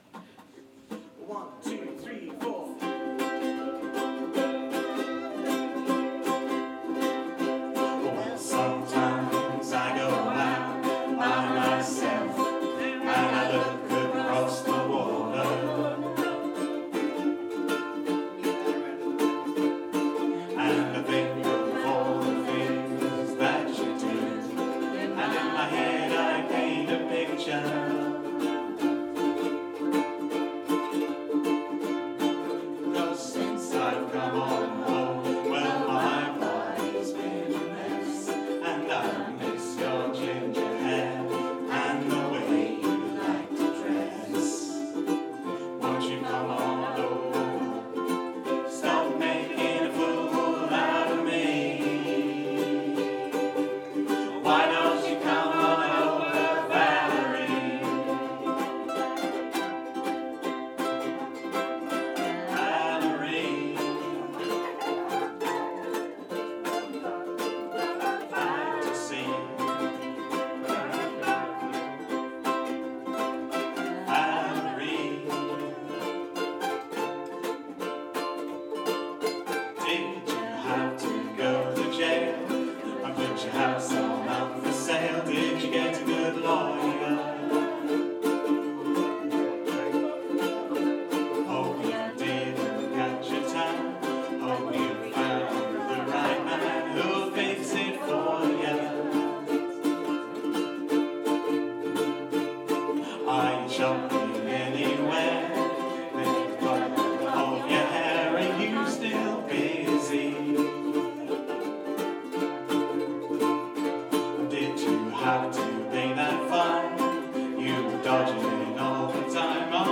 West End Class at The Lion